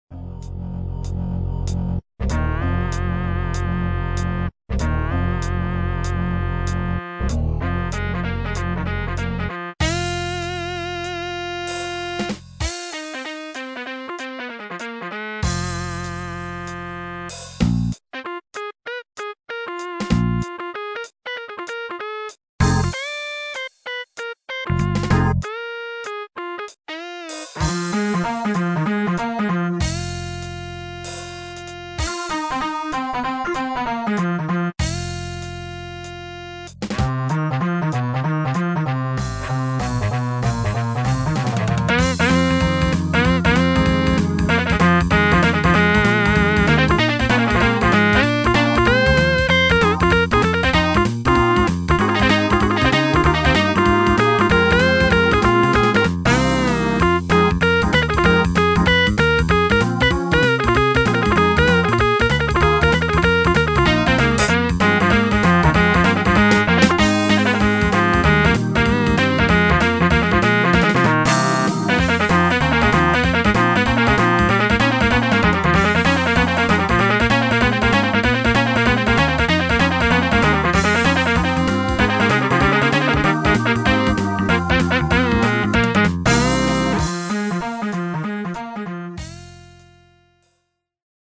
Rock 70's
MIDI